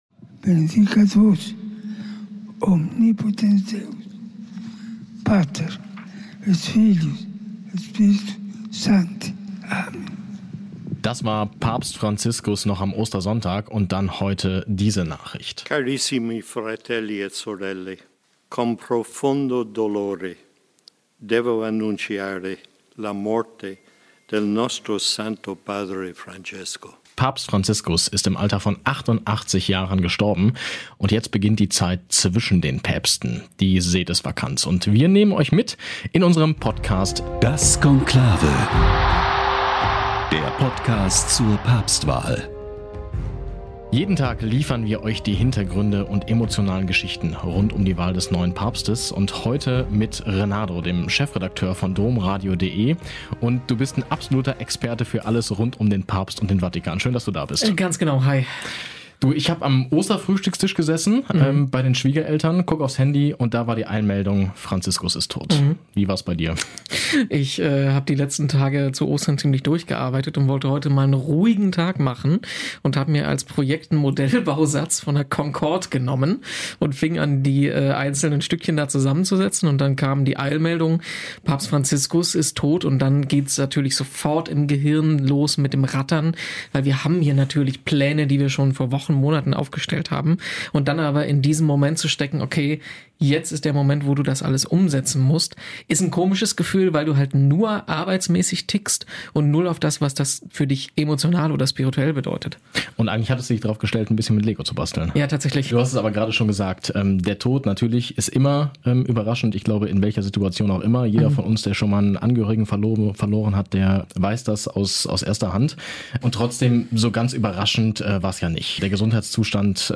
Welche Erinnerungen bleiben, und warum war Franziskus so besonders? Mit persönlichen Stimmen von Menschen, die ihm nahe standen – unter anderem mit dem Luxemburger Kardinal Hollerich und dem Kölner Kardinal Woelki.
Ein täglicher Blick hinter die Kulissen, jenseits von Schlagzeilen: verständlich, spannend und authentisch moderiert.